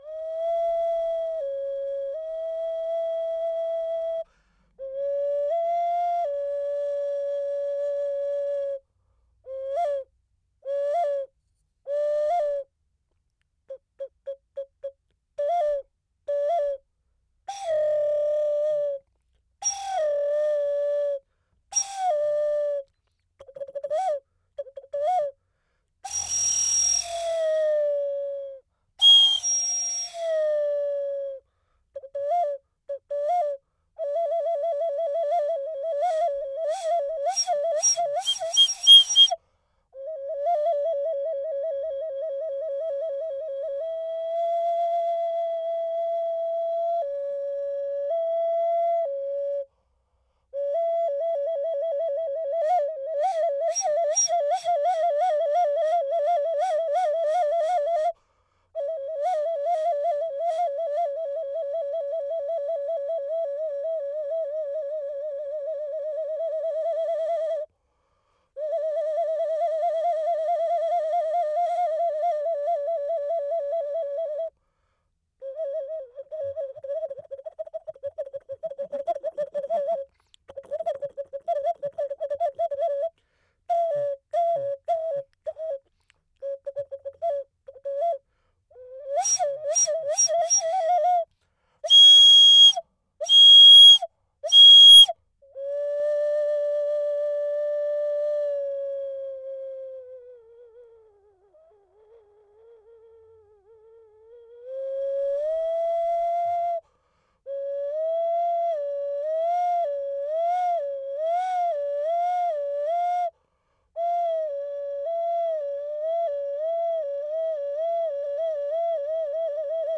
Audio 4. Silbato de cerámica en forma de “mujer-pájaro”, contexto ritual del Patio 1, Complejo Oeste del Grupo B (fig. 7e).